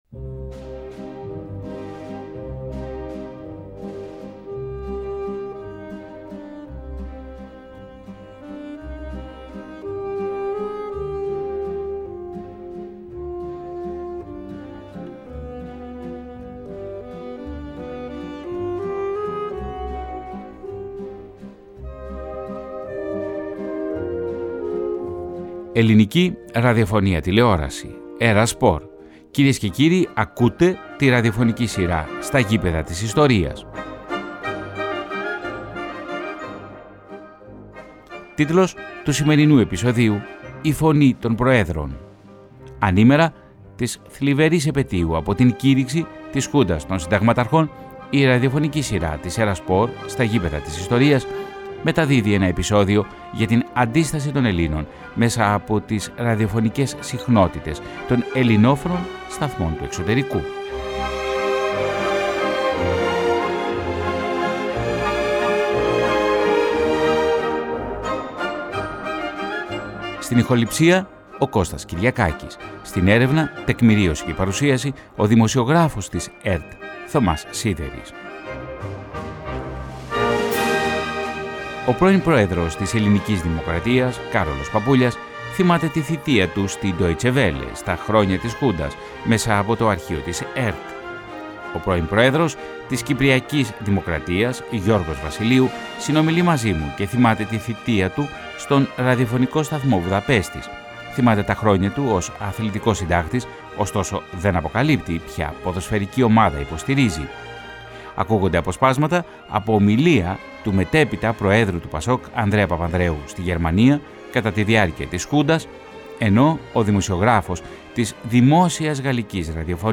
ακούγονται αποσπάσματα από ομιλία του μετέπειτα προέδρου του ΠΑΣΟΚ Ανδρέα Παπανδρέου στη Γερμανία κατά τη διάρκεια της Χούντας
ακούγονται αποσπάσματα από το συγκλονιστικό κείμενο που έγραψε και διάβασε στο γαλλικό ραδιόφωνο όταν έπεσε η Χούντα.
ΝΤΟΚΙΜΑΝΤΕΡ